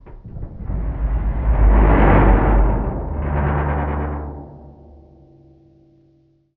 metal_low_creaking_ship_structure_10.wav